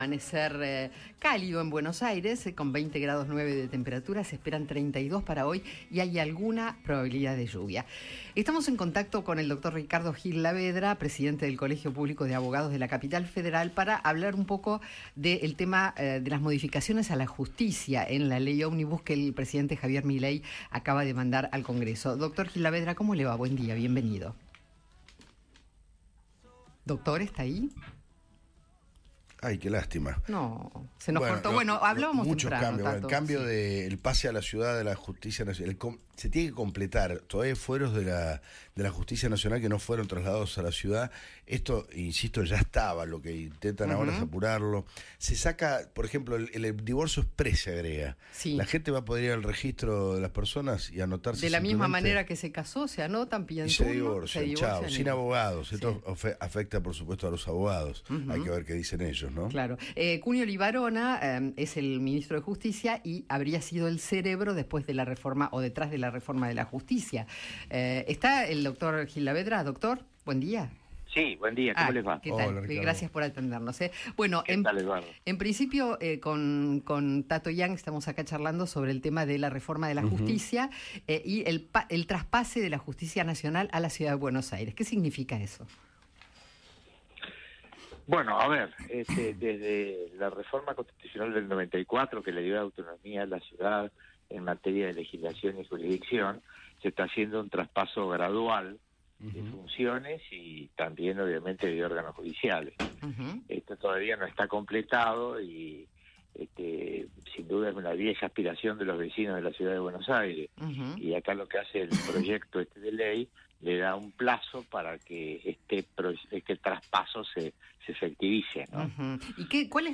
Ricardo Gil Lavedra,  Presidente del Colegio Público de Abogados de la Capital Federal, habló con el equipo de Alguien Tiene que Decirlo y analizó el divorcio exprés y la prisión efectiva para piqueteros que propone el proyecto Ómnibus del Gobierno.